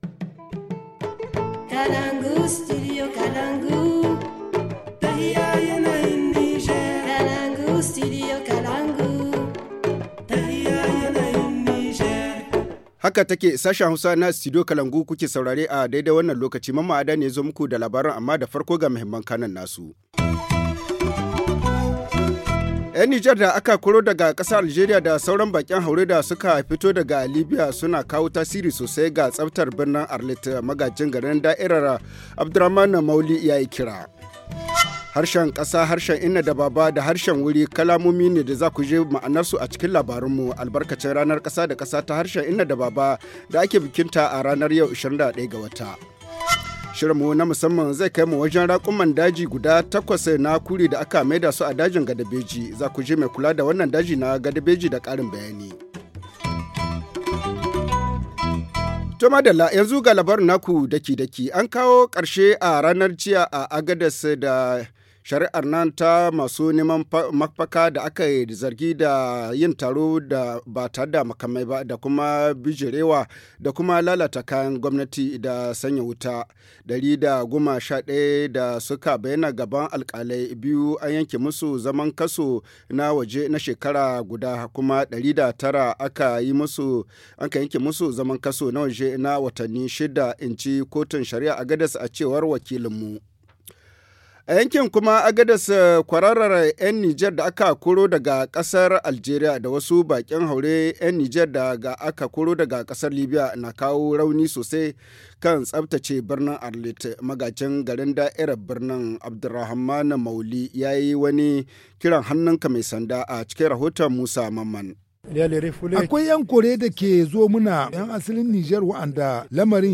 Le journal du 21 février 2020 - Studio Kalangou - Au rythme du Niger